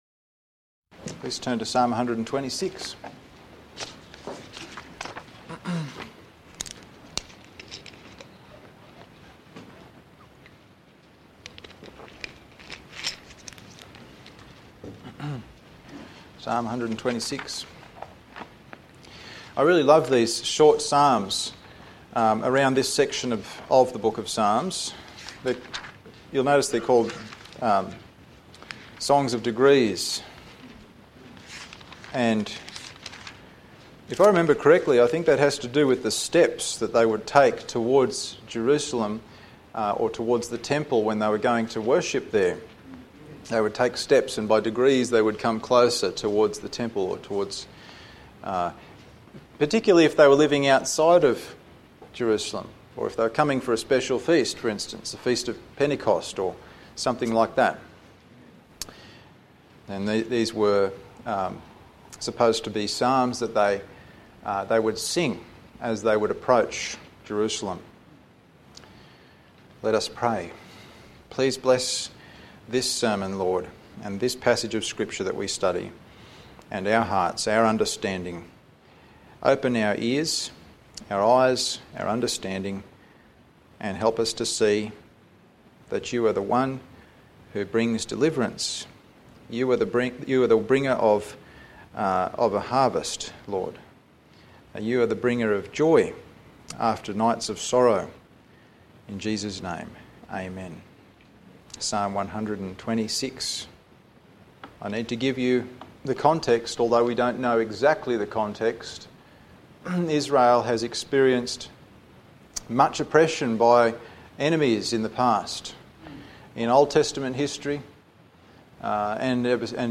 Sermons | TBBC | Tamworth Bible Baptist Church
Service Type: Sunday Morning